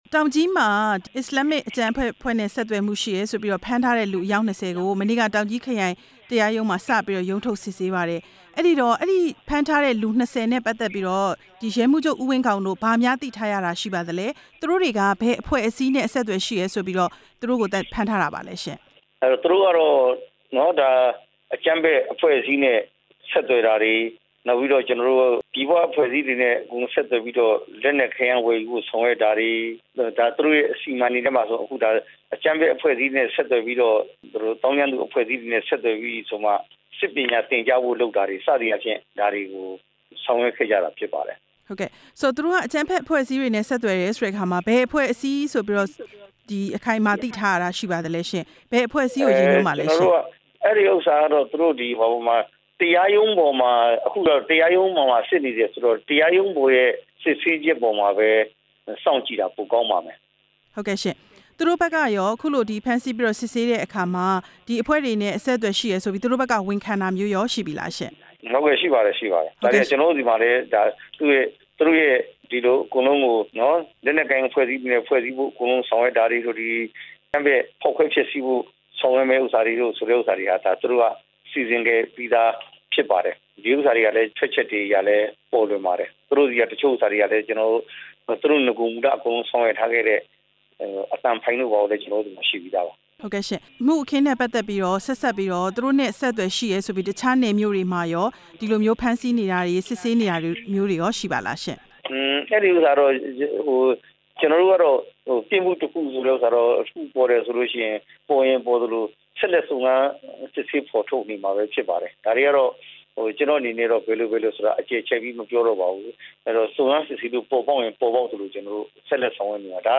တောင်ကြီးမှာ ဖမ်းဆီးခံထားရသူ ၂၀ ဦးအကြောင်း ရဲမှူးချုပ်နဲ့ မေးမြန်းချက်